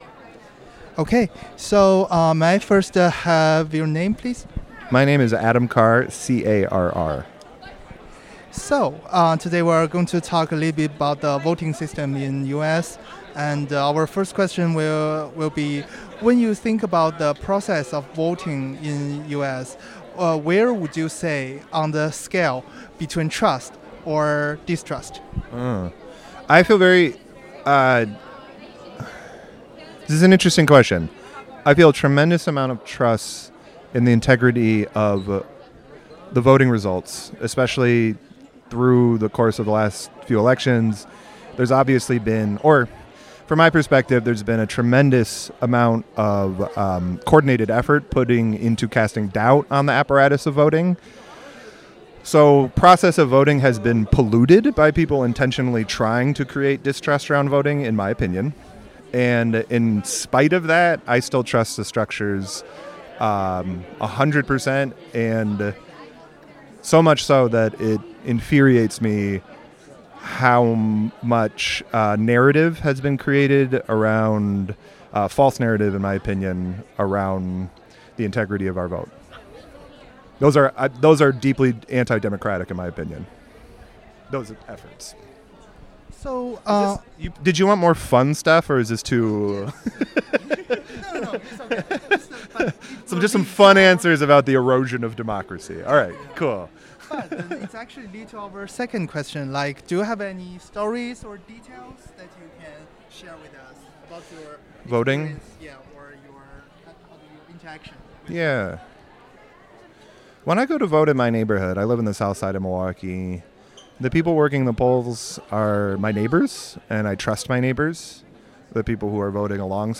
Location UWM Student Union